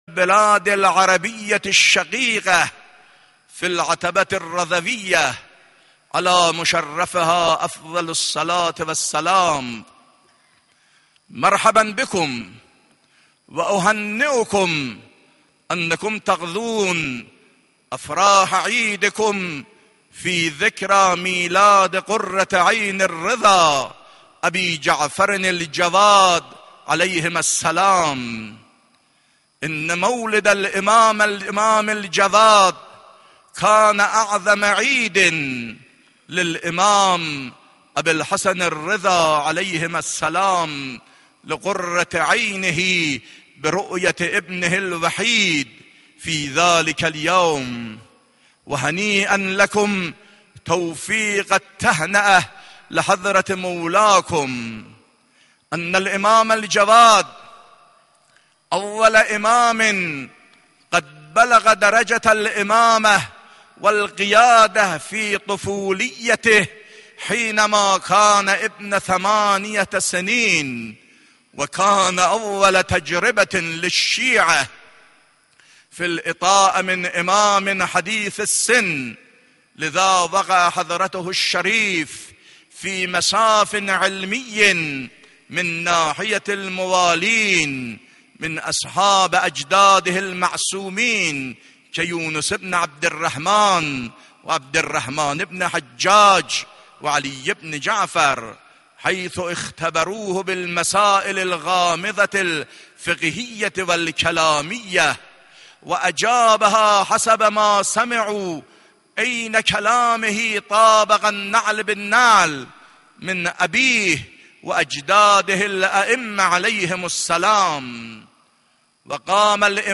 خطبه عربی.mp3
خطبه-عربی.mp3